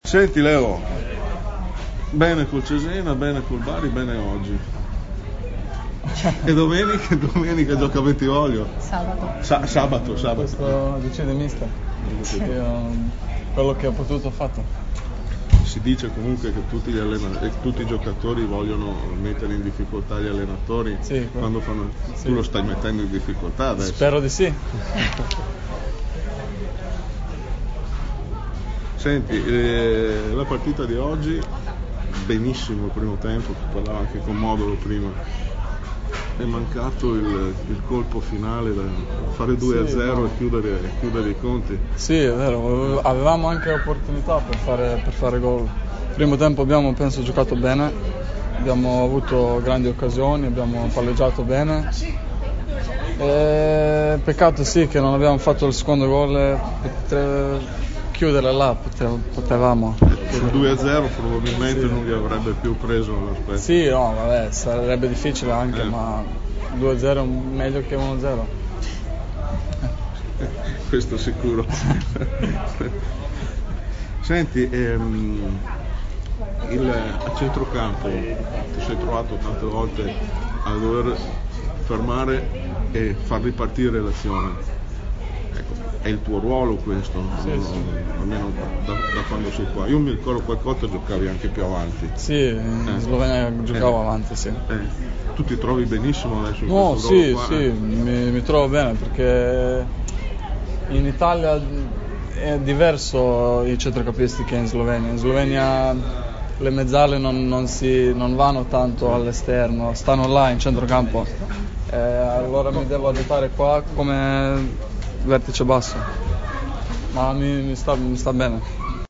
Queste le dichiarazioni di Leo Stulac rilasciate nella sala stampa del Picco dopo il pareggio conquistato dal Venezia a La Spezia: “Alla prossima gioca Bentivoglio?
int_stulac_post_spezia.mp3